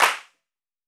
Index of /90_sSampleCDs/300 Drum Machines/Akai MPC-500/1. Kits/Fusion Kit
Stereo Clap.WAV